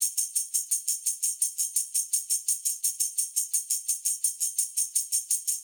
Index of /musicradar/sampled-funk-soul-samples/85bpm/Beats
SSF_TambProc2_85-03.wav